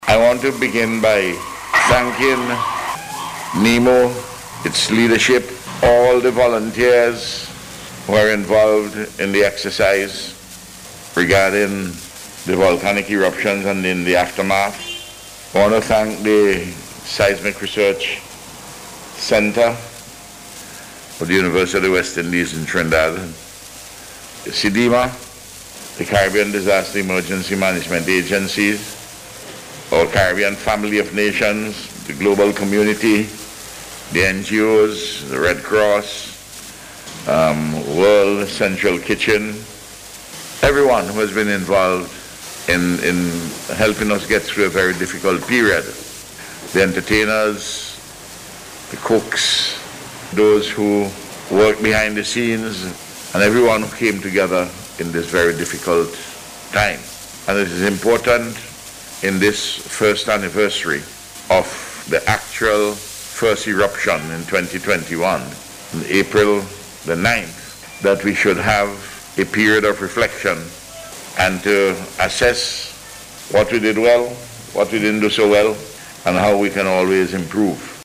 The Prime Minister made the point while delivering the feature address at the launch of Volcano Awareness Month yesterday.